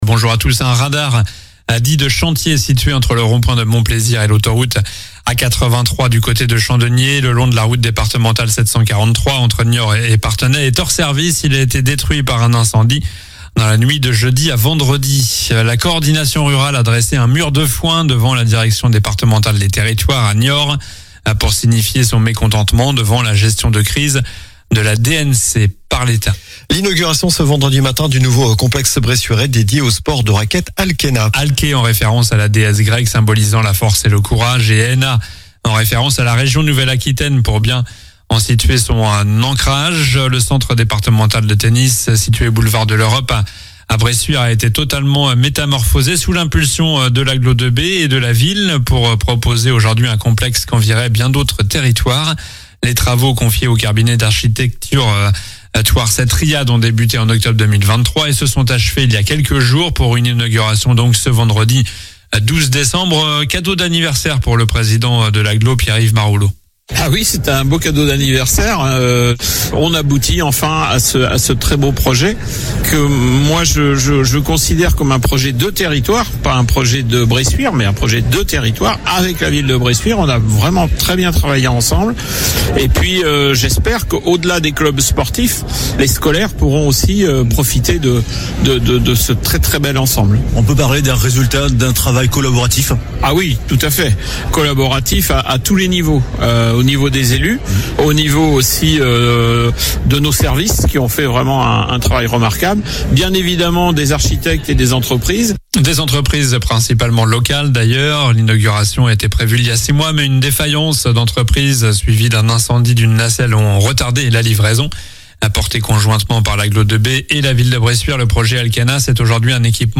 Journal du samedi 13 décembre